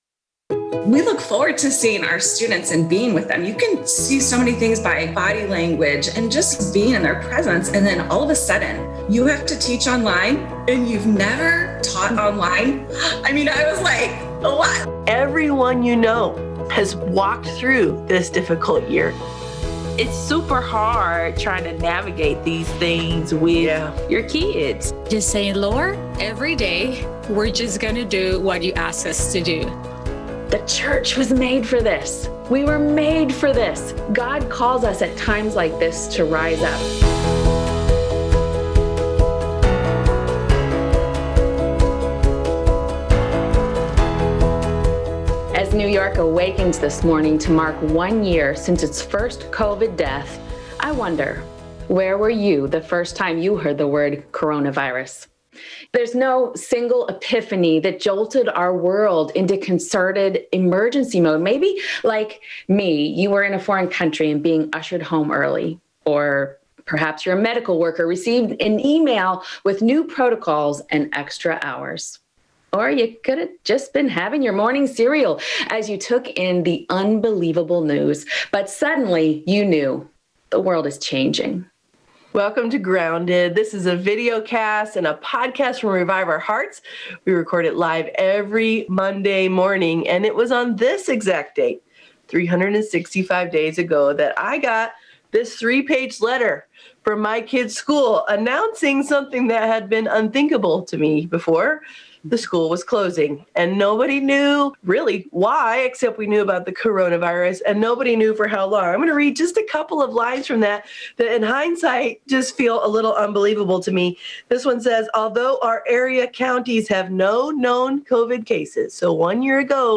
In this episode, our Grounded hosts reflect on the state of our world as the pandemic began, acknowledge the struggles of the last twelve months, and celebrate God’s goodness.